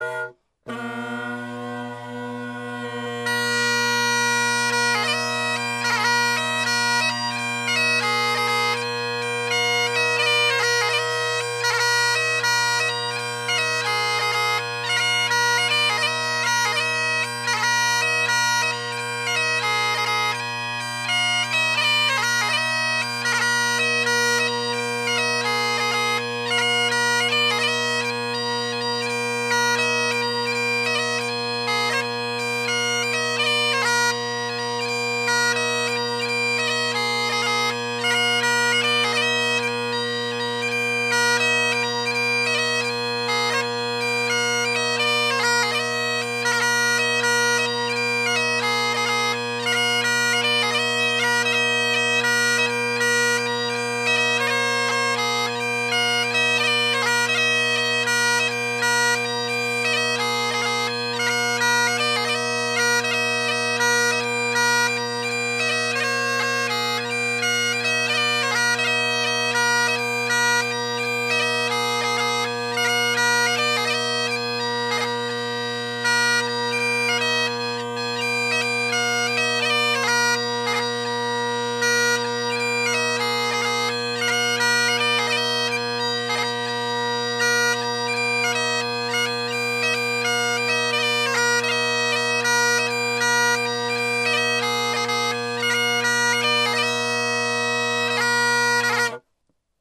Drone Sounds of the GHB, Great Highland Bagpipe Solo
In the recordings below I set the high A just a tad flat as I’m lazy and taped the high G, F, and E. Note the C isn’t flat, even from the get go, which is cool.
Mrs. Lily Christie – AyrFire chanter – MacLellan reed – MacPherson bagpipes – Henderson Harmonic Deluxe tenor reeds – Kinnaird bass